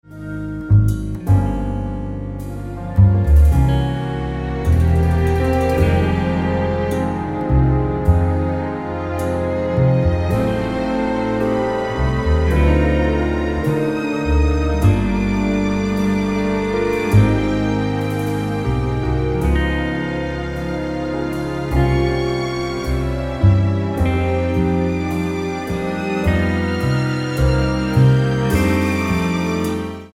--> MP3 Demo abspielen...
Tonart:G ohne Chor